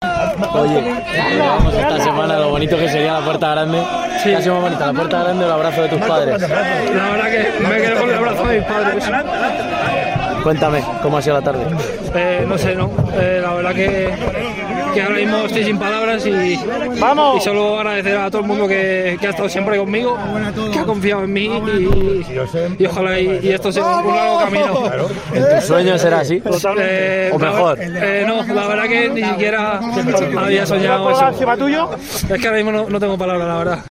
tras abrir la Puerta Grande de Las Ventas